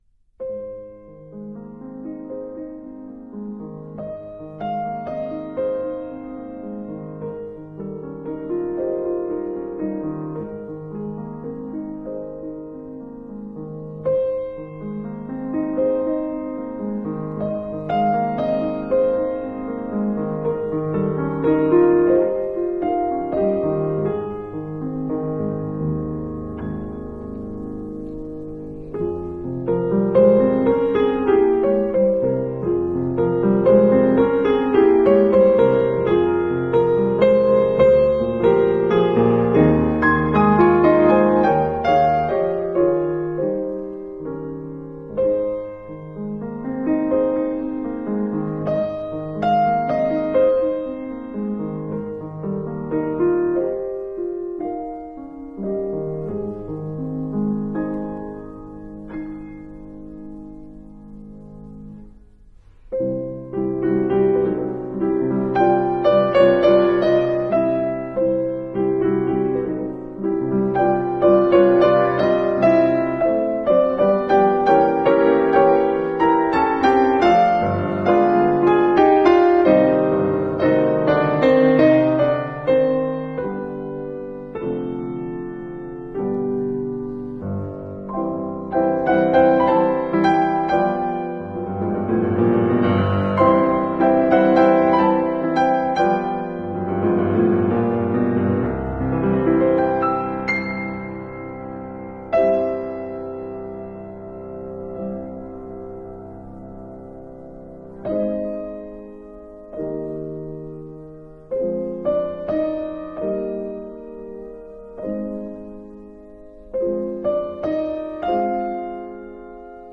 Today’s programme has the theme Romantic Reflections, and I present for your enjoyment these 12 items which are designed to leave you relaxed and calm.